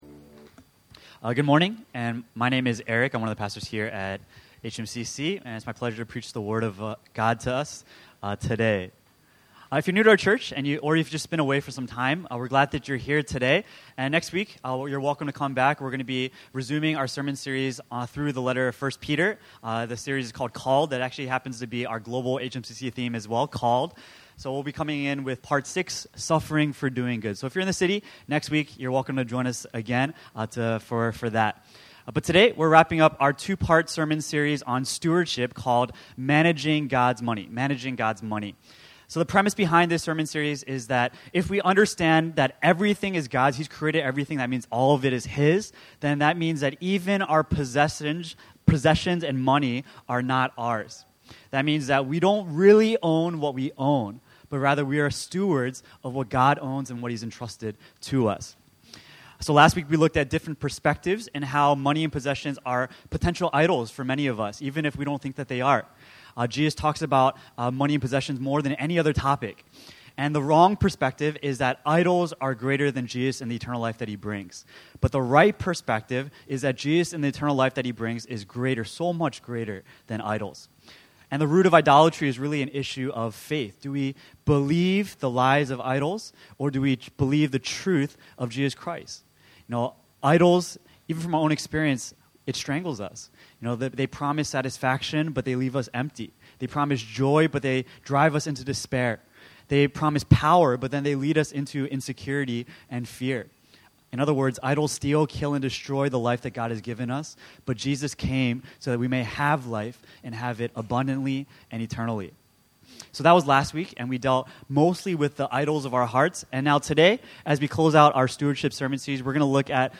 So as a church, we want to be equipped both in the principles and practicals of “managing God’s money.” In this two-part stewardship sermon series, we’ll look at “Different Perspectives” on money and how we ought to use money for “Strategic Living” in light of the Gospel.